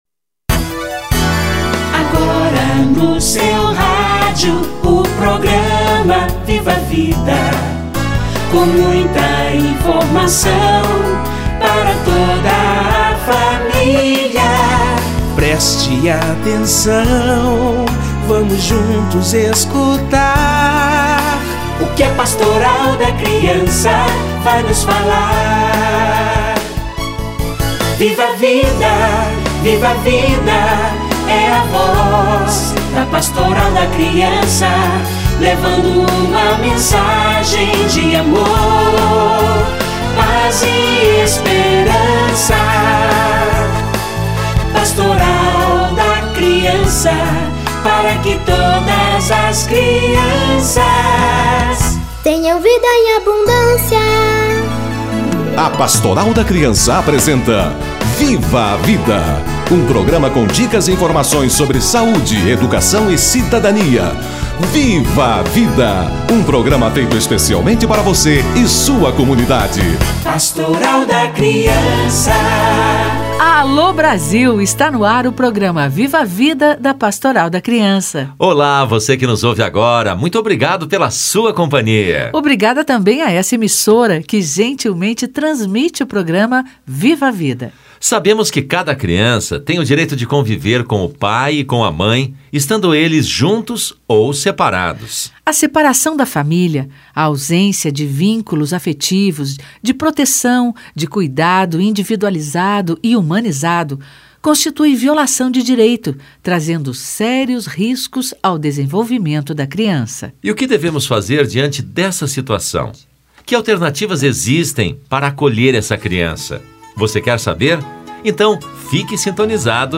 Políticas públicas para a infância - Entrevista